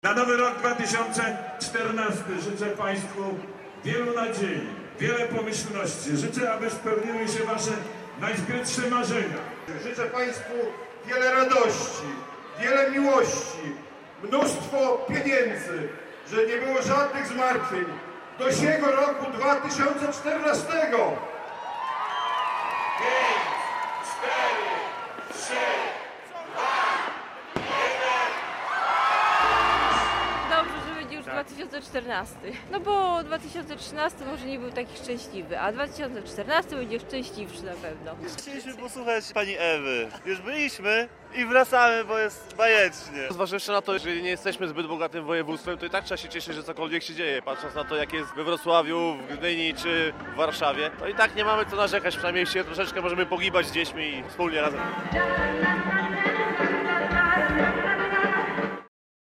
Kilka tysięcy osób żegnało stary i witało nowy 2014 rok na Rynku Kościuszki w Białymstoku.